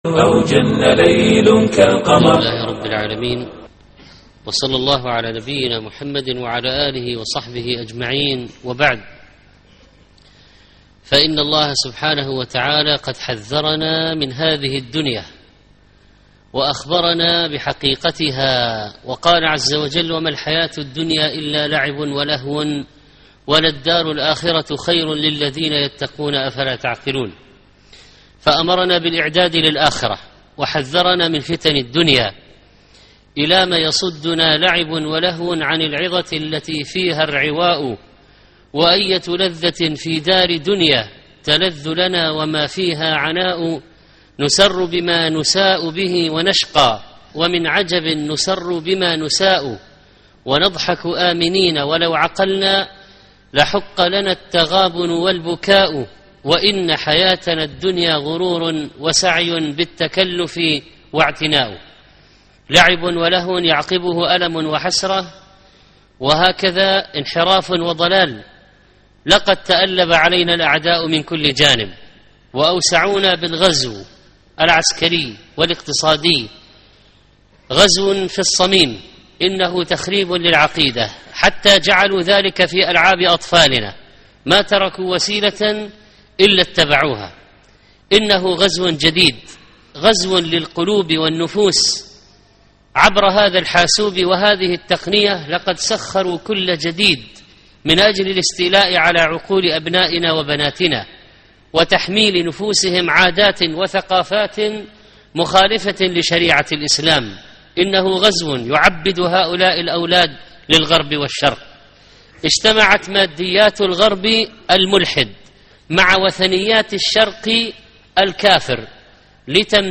حمى الألعاب الإلكترونية (25/3/2012) محاضرة اليوم - الشيخ محمد صالح المنجد